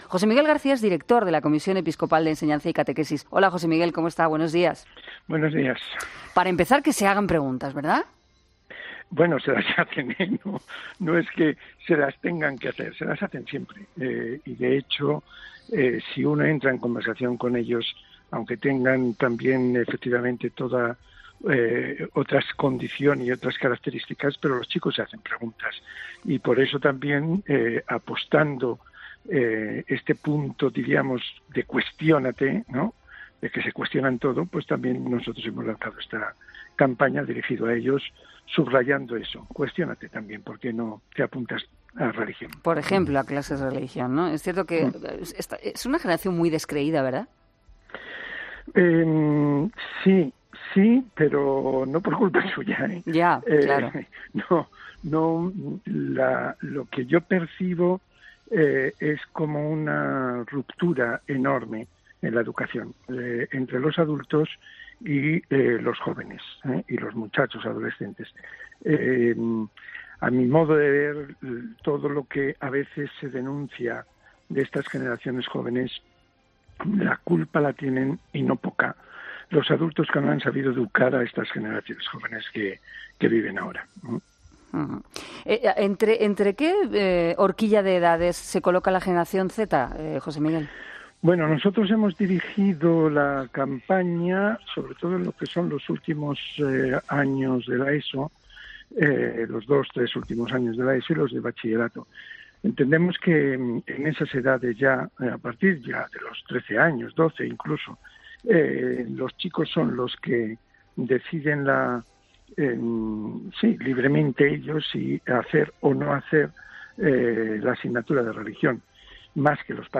Entrevista
Con Carlos Herrera